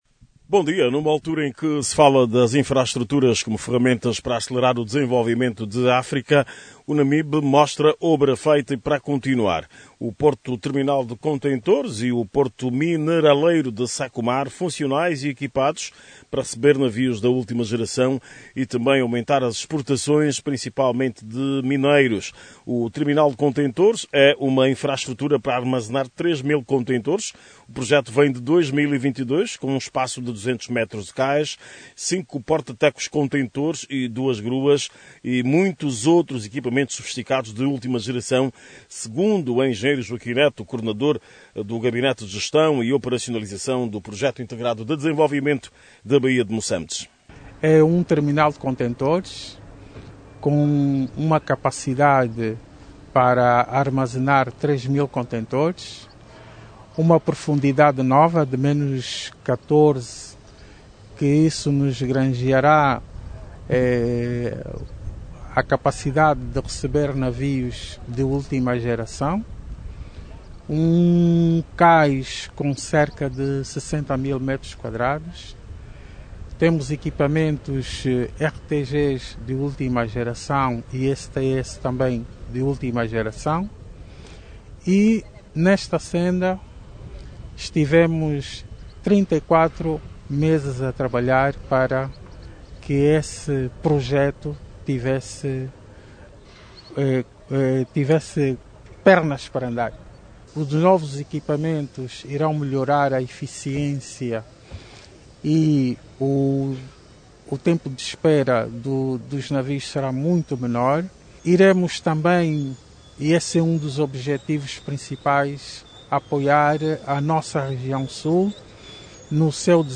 directamente da cidade de Moçâmedes, na Província do Namibe.